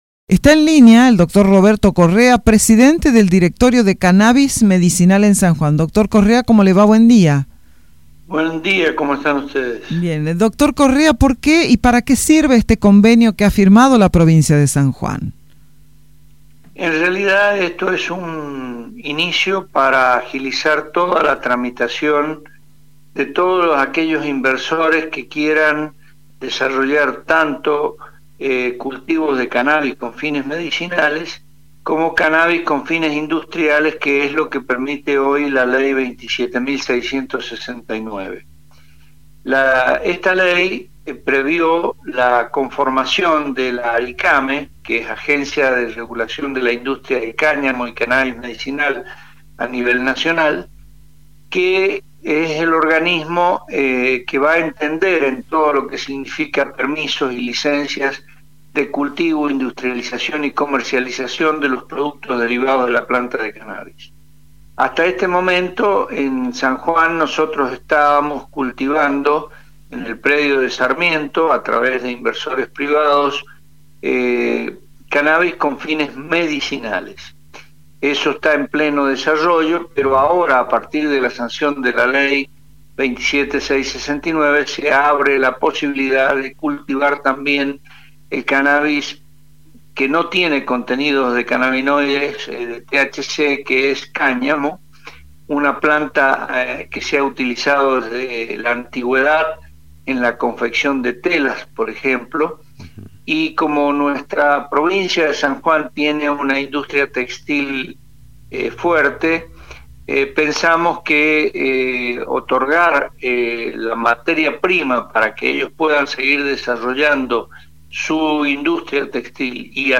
Roberto Correa, presidente del directorio de Cannabis de la provincia, en dialogo con Radio Sarmientó, habló sobre esta agencia «Esto es un inicio para agilizar toda la tramitación de todos aquellos inversores que quieran desarrollar tanto cultivo de cannabis con fines medicinales, cómo Cannabis con fines industriales».